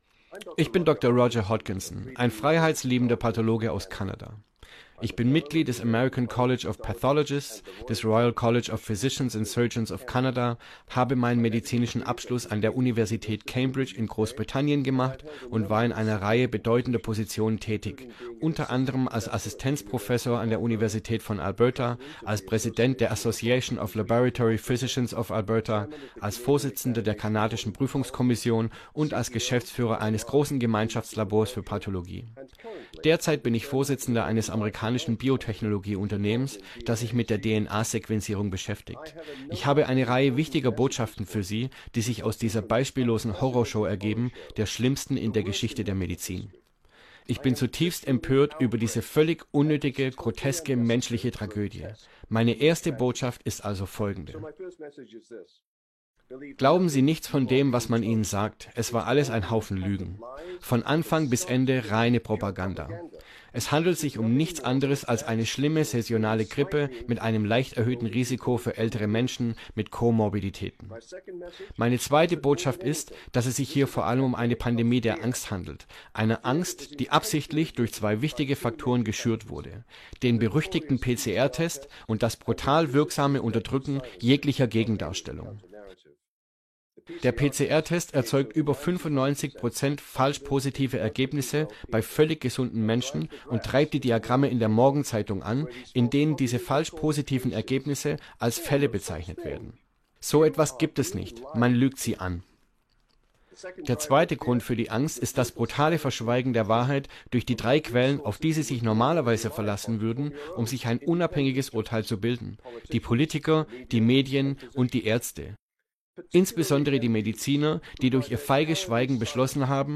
Bei einer öffentlichen Sitzung in Alberta, Kanada, wandte er sich an die Regierungsvertreter sowie die Allgemeinheit.